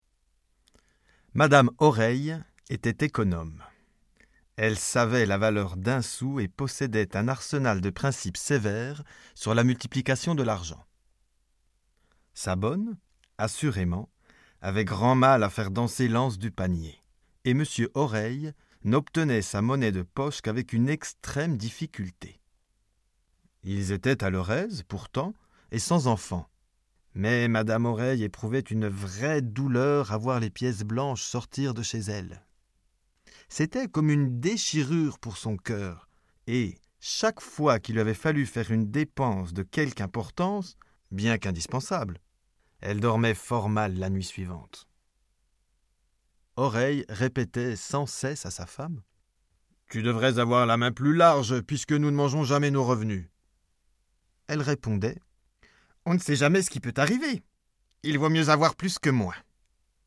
Inclus dans l’audiobook : Le Parapluie, La ficelle, La parure, La dot × Guide des formats Les livres numériques peuvent être téléchargés depuis l'ebookstore Numilog ou directement depuis une tablette ou smartphone.